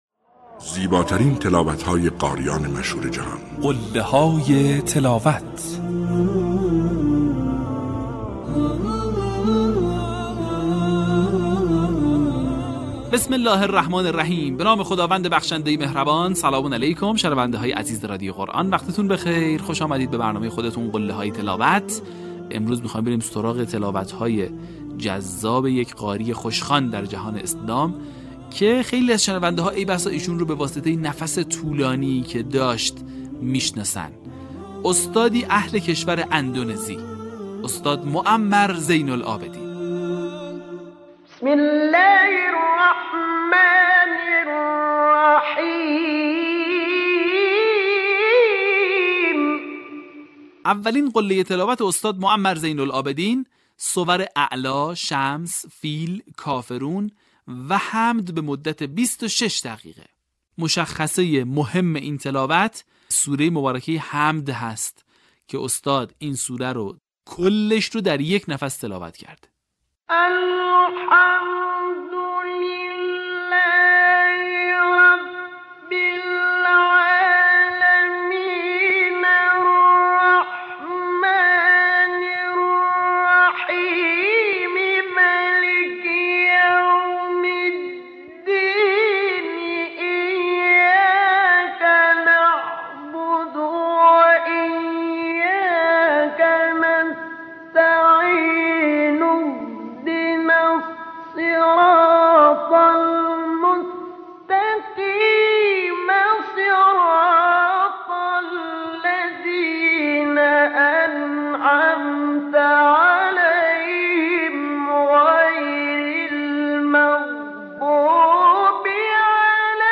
برچسب ها: معمر زین العابدین ، قله های تلاوت ، فراز تقلیدی ، تلاوت ماندگار